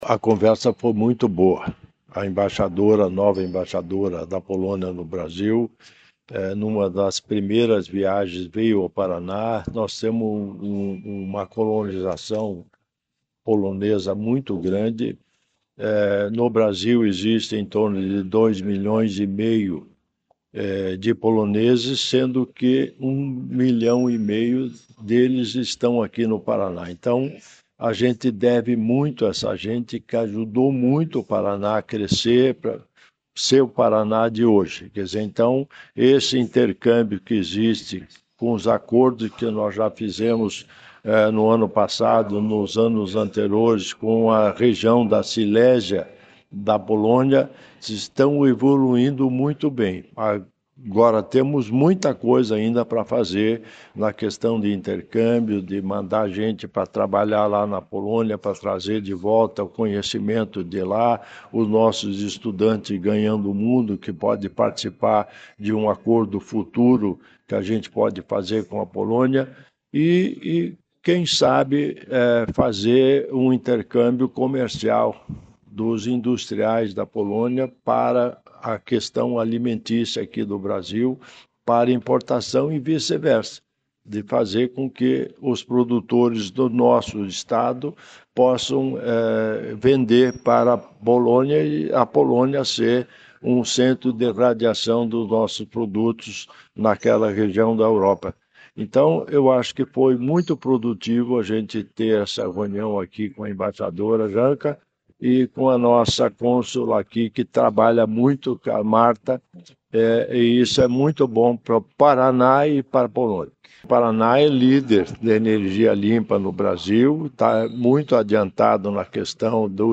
Sonora do vice-governador Darci Piana sobre a apresentação de projetos de cooperação com para a embaixadora da Polônia